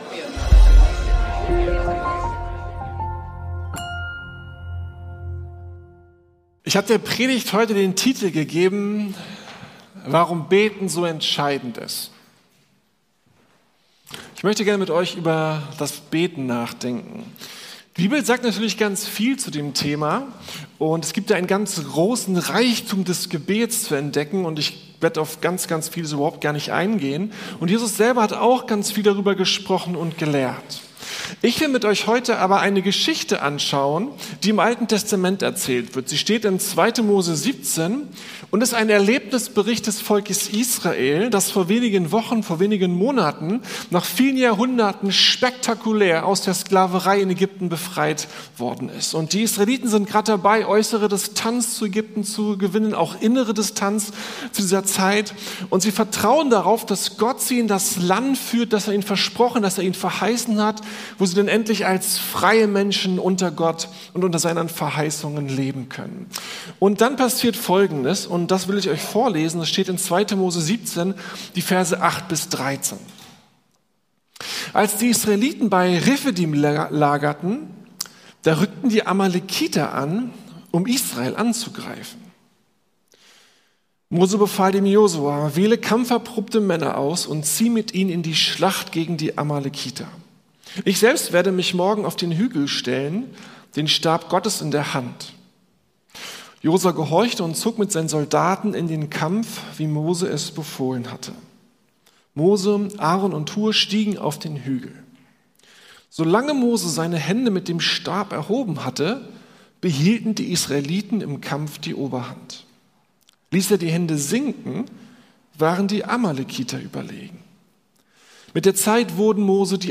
Warum beten so entscheident ist ~ Predigten der LUKAS GEMEINDE Podcast